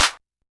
Southside Clapz (7).wav